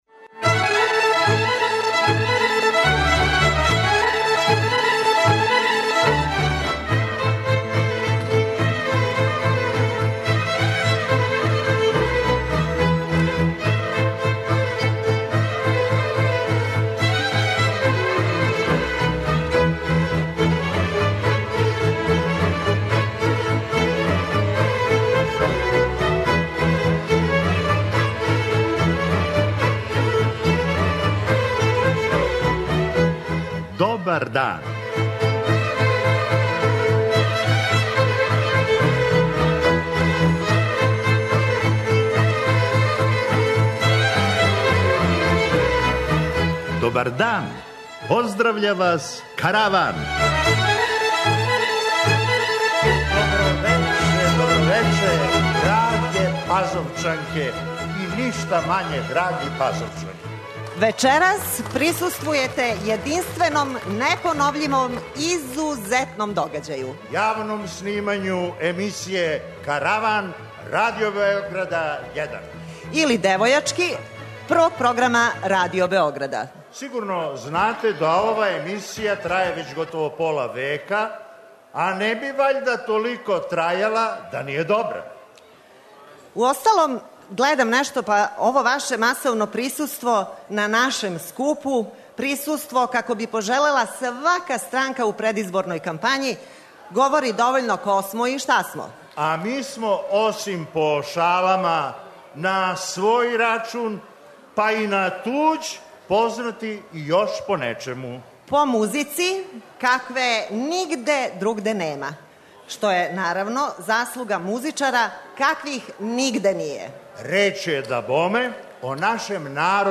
Житељи Старе Пазове имали су прилику да буду део јединственог и непоновљивог догађаја - присуствовали су јавном снимању Каравана, а ви сте данас у прилици да чујете управо тај старопазовски Караван!
преузми : 23.24 MB Караван Autor: Забавна редакција Радио Бeограда 1 Караван се креће ка својој дестинацији већ више од 50 година, увек добро натоварен актуелним хумором и изворним народним песмама.